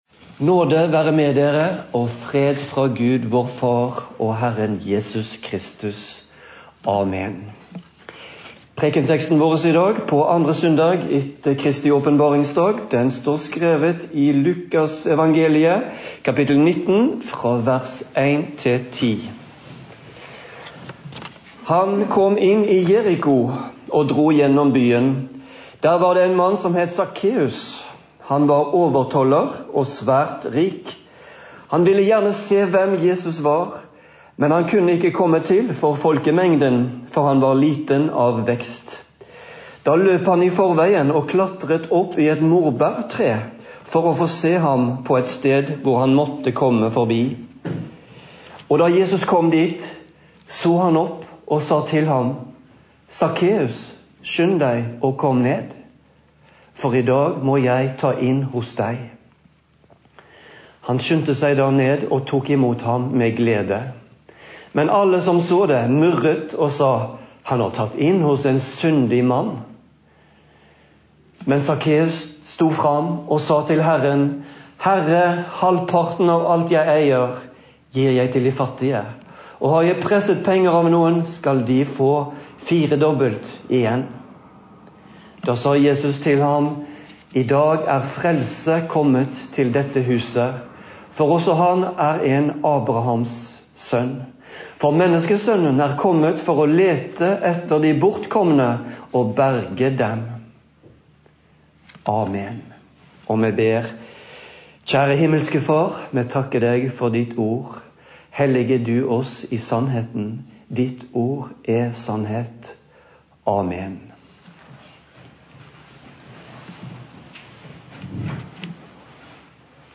Preken
Sakkeus-preken.mp3